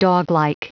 Prononciation du mot doglike en anglais (fichier audio)
Prononciation du mot : doglike